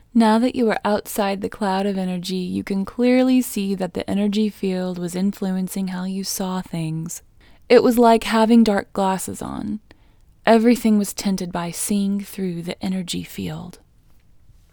OUT Technique Female English 30